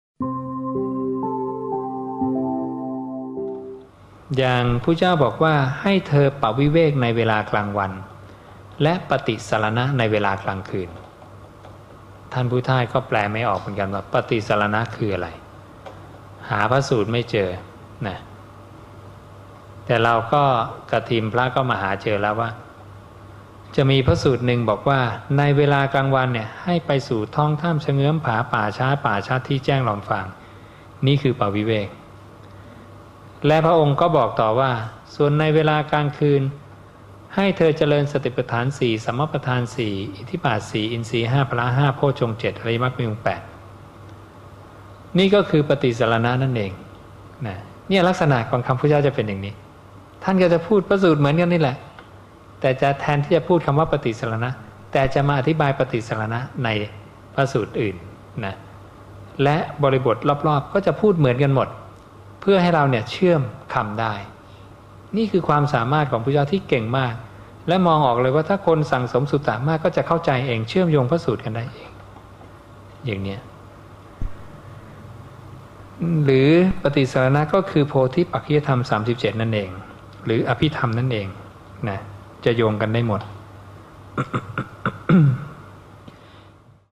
บางส่วนจากการแสดงธรรม ณ ยุวพุทธธิกสมาคม 9 ส.ค. 55